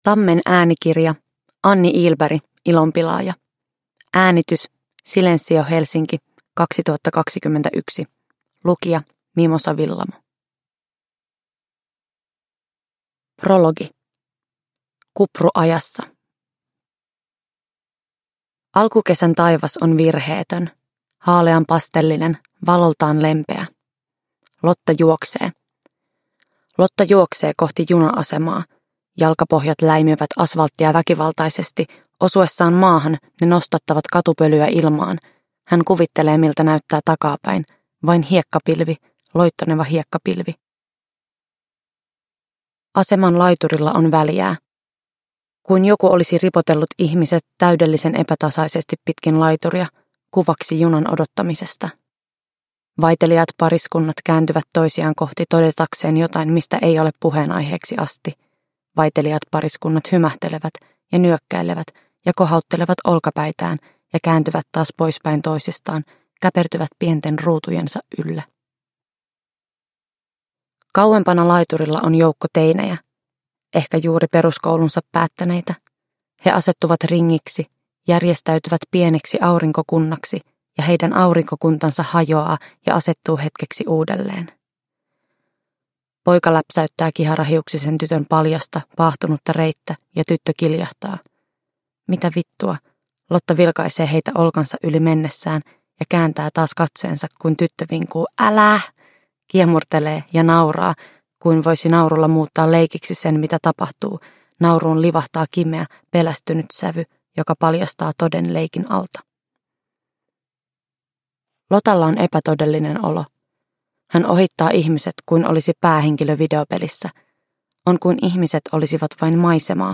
Ilonpilaaja – Ljudbok – Laddas ner